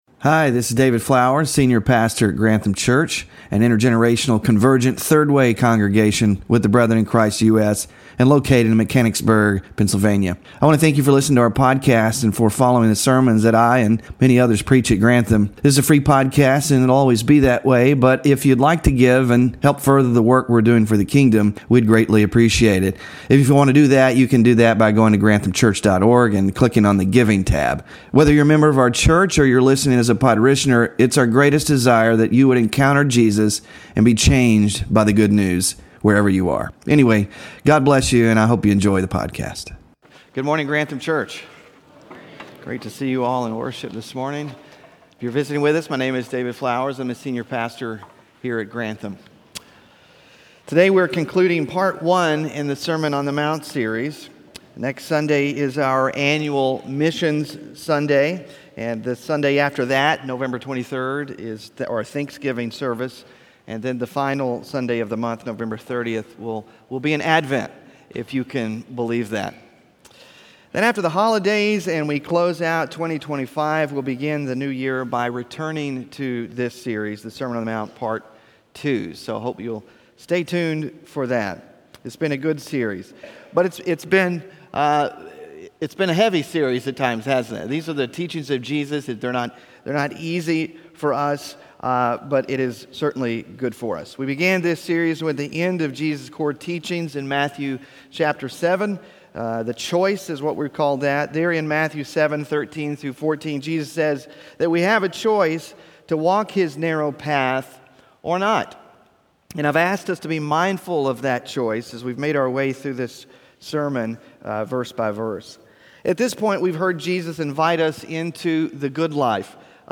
Sermon Focus: In Matthew 6:1-18, Jesus wants us to consider what it looks like to live out a greater righteousness through three religious practices: giving, prayer, and fasting.